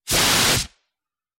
Звуки огнетушителя
На этой странице собраны звуки огнетушителя: шипение пены, нажатие рычага, распыление состава.